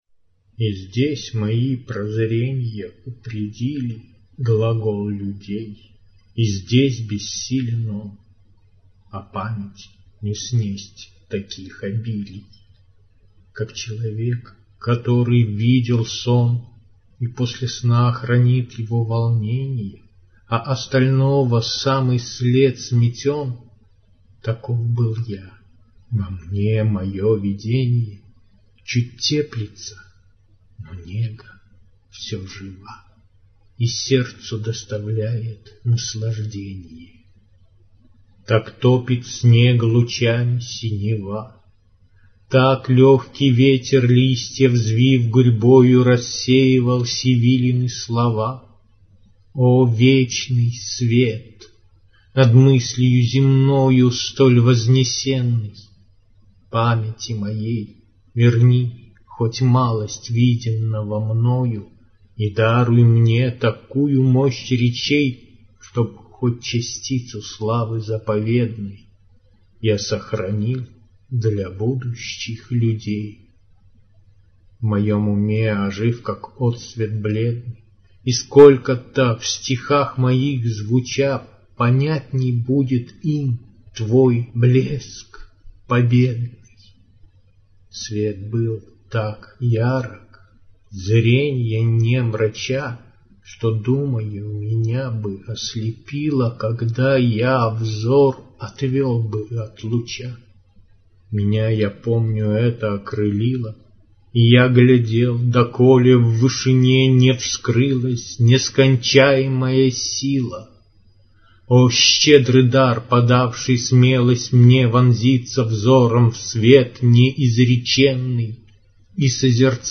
звучащие стихи
для тех, кто слушает стихи